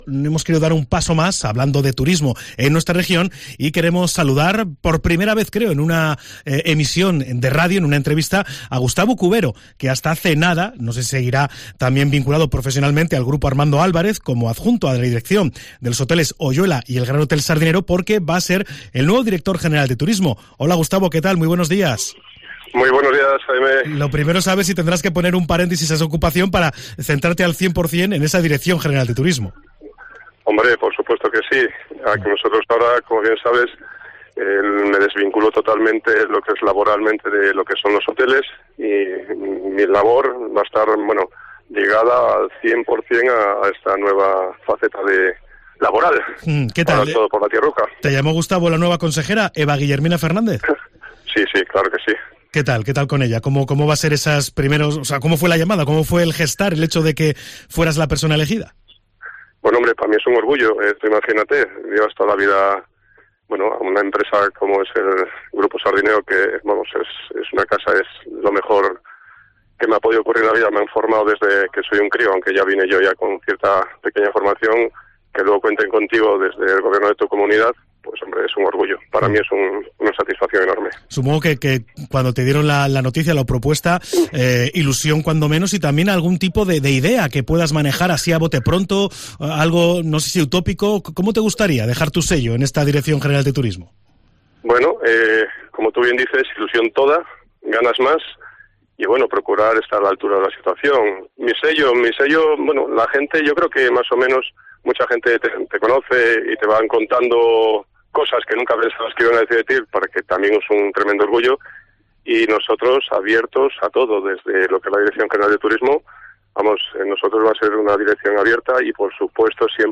Escucha a Gustavo Cubero, nuevo director general de turismo del Gobierno de Cantabria, en Cope